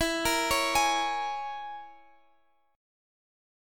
Listen to E6b5 strummed